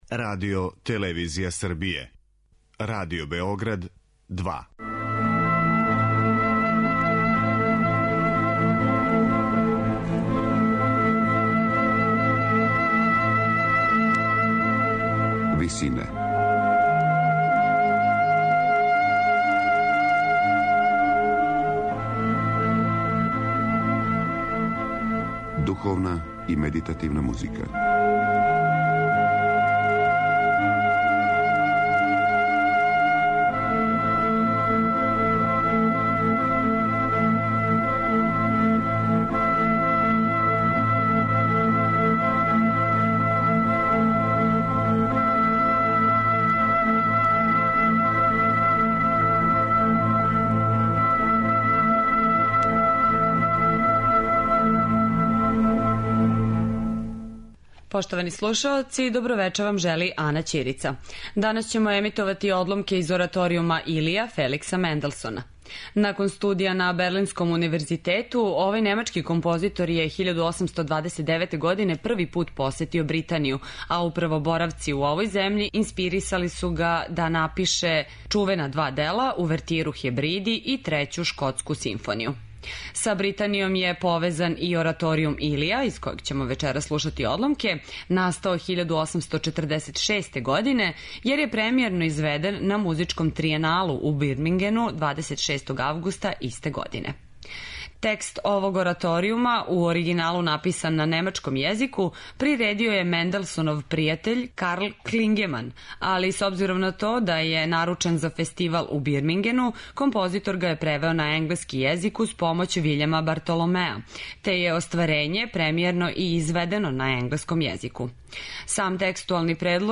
Феликс Менделсон: ораторијум 'Илија'